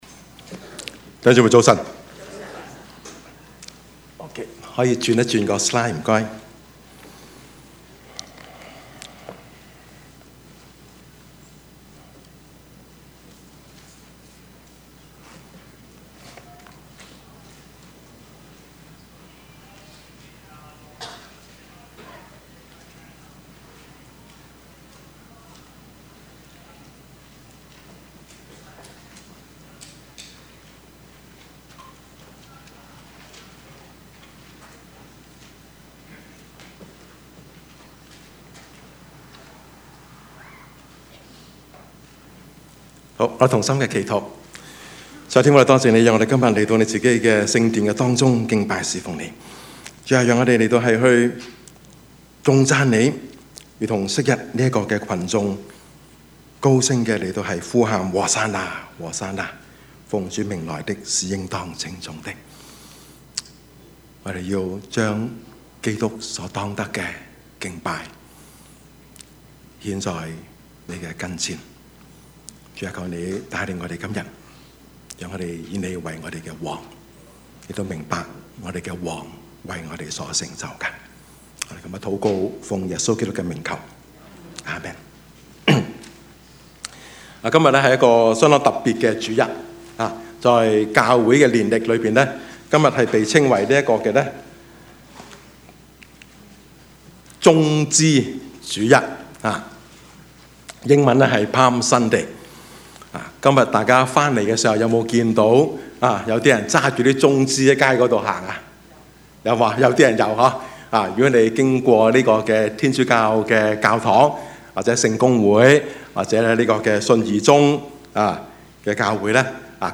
Service Type: 主日崇拜
Topics: 主日證道 « 痛而不苦 做好人可以上天堂嗎?